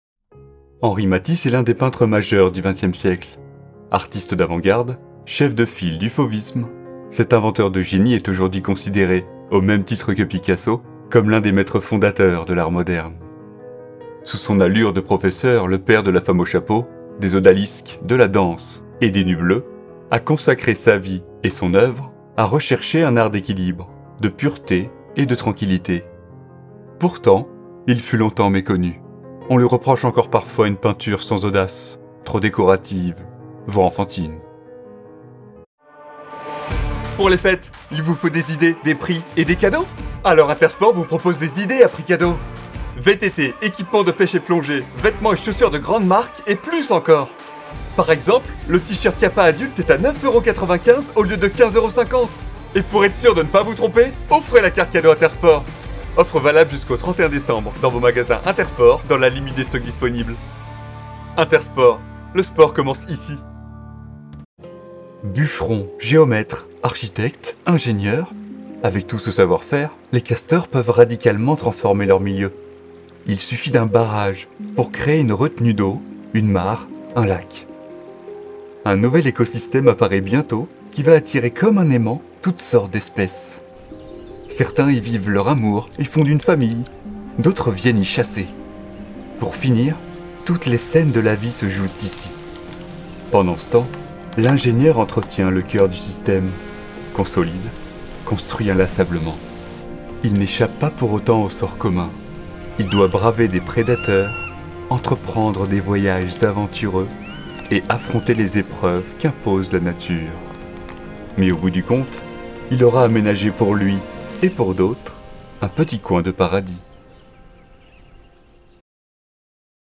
Voix off
Bande démo voix-off (Documentaire - Publicité)